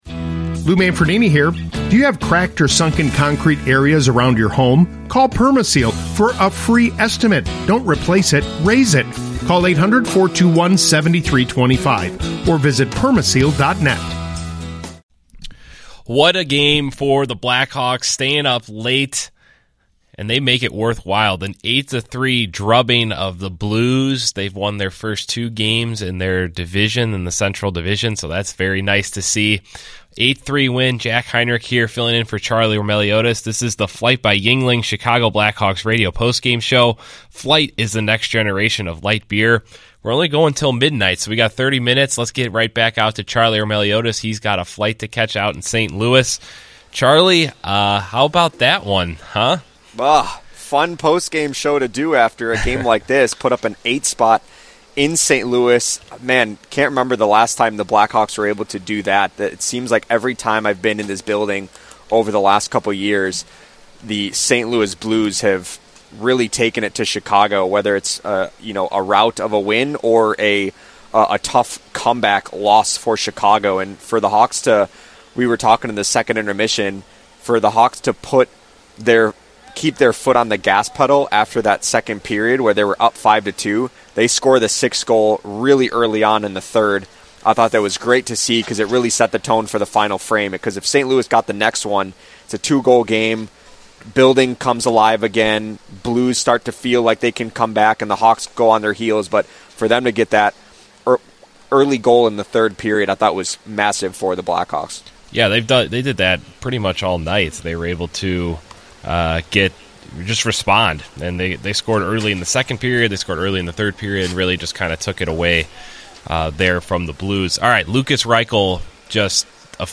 Plus, we hear from Lukas Reichel and Frank Nazar to talk about Chicago’s performance in this rivalry game.